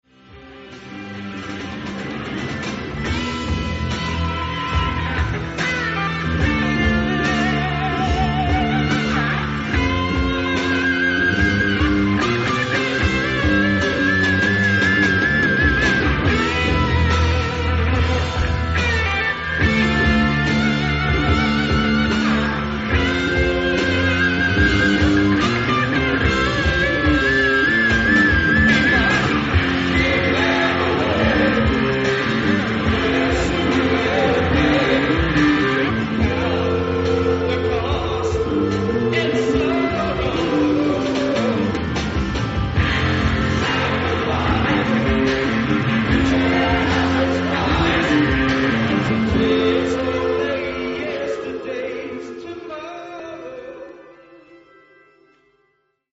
Sound:  Remastered
Source:  Soundboard